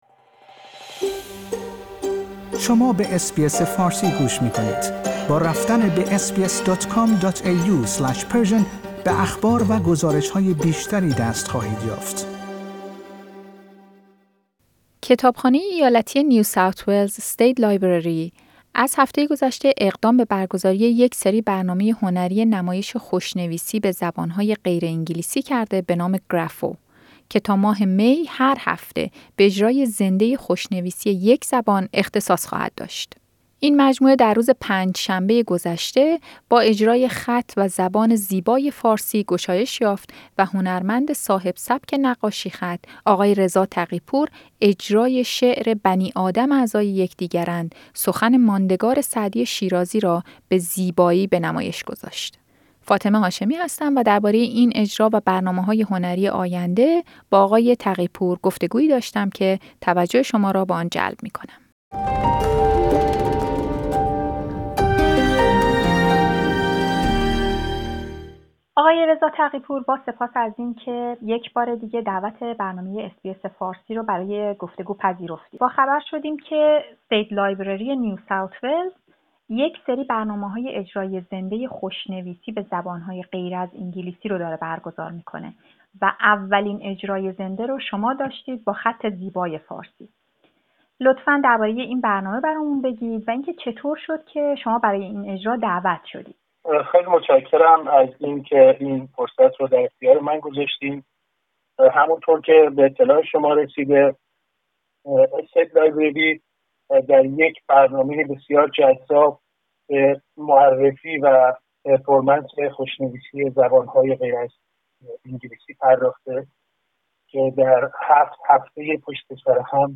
گفتگویی داشتیم.